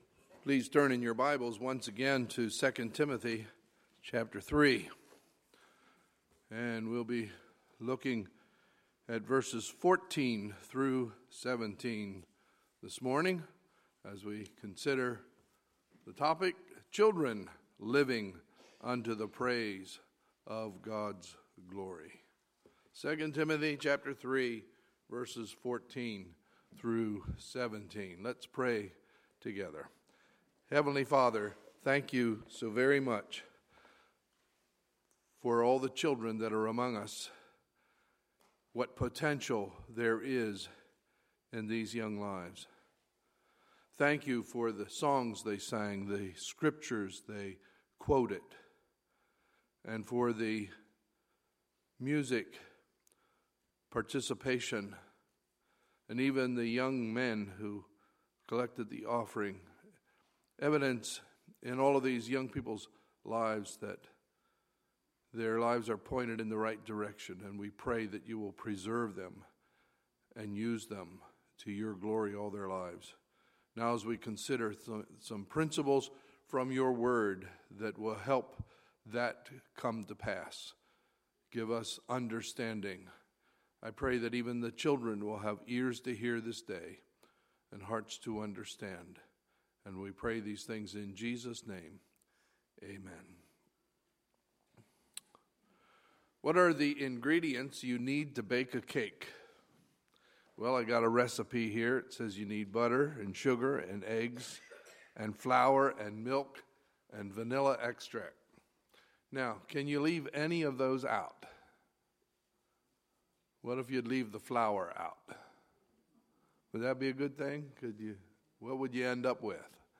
Sunday, May 15, 2016 – Sunday Morning Service